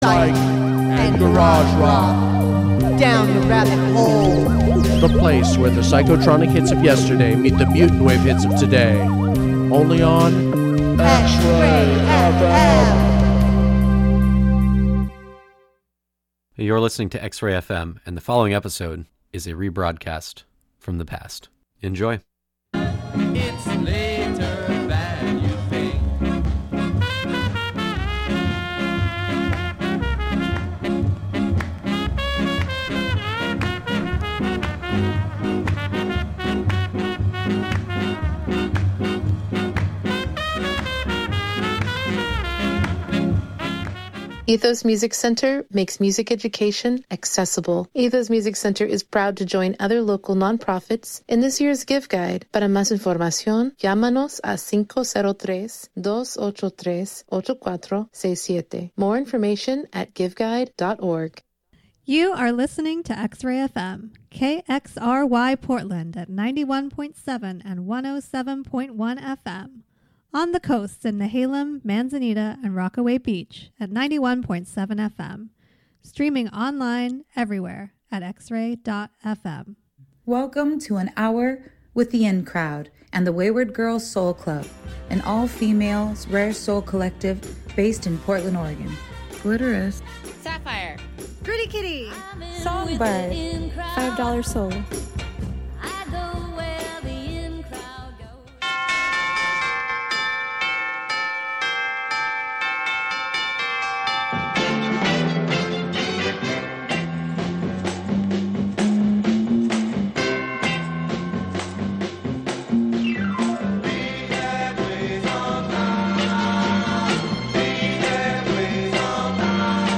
soul songs